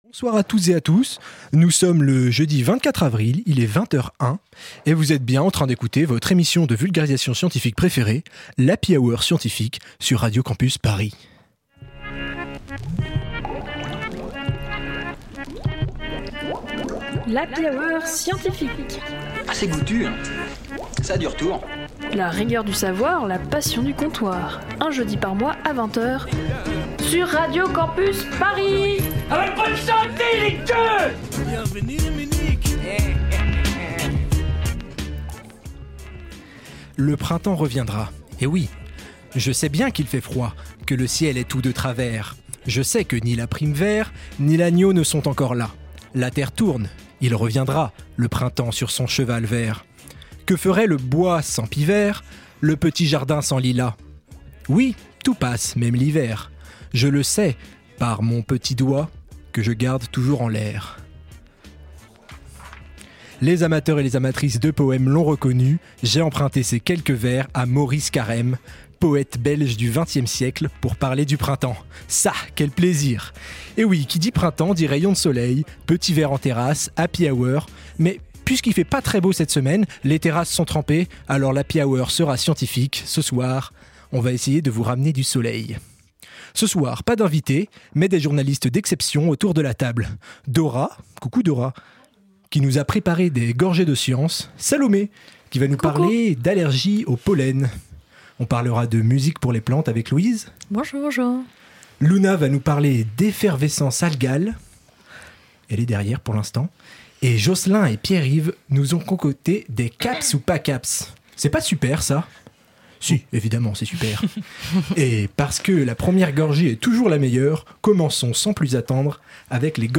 Type Magazine Sciences